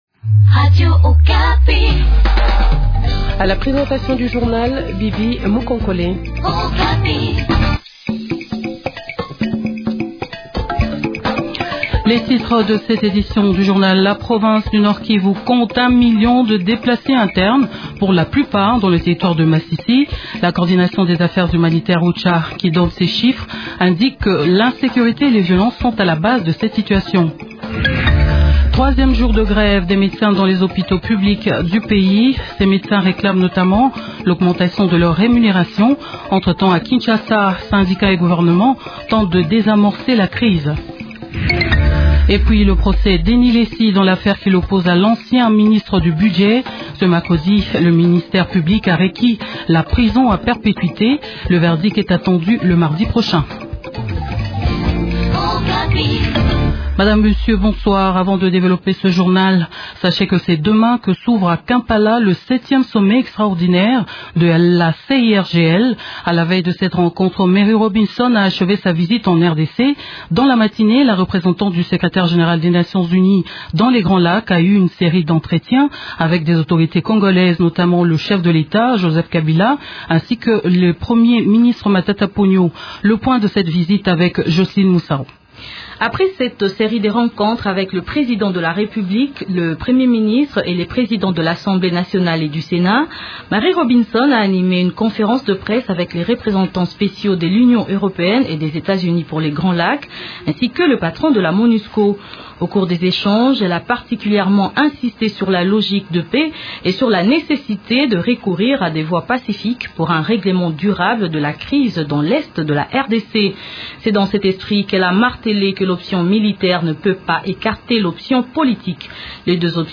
Journal Français Soir